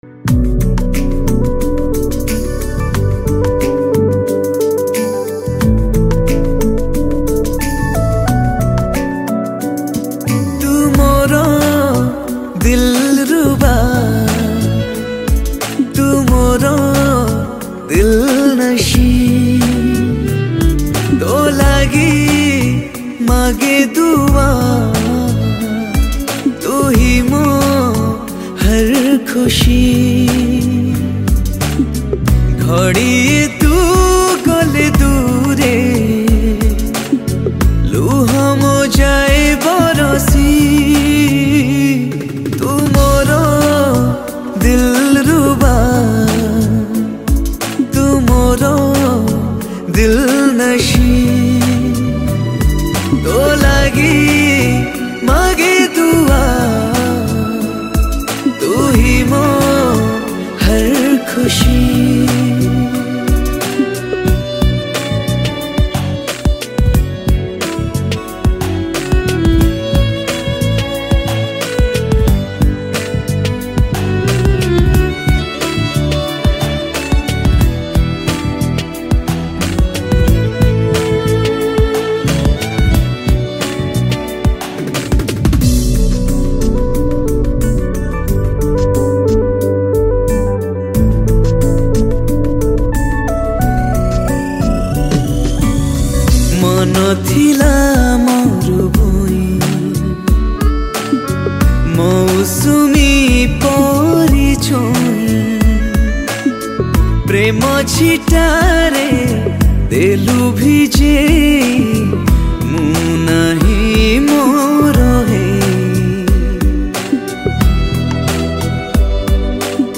Romantic Song
Odia Songs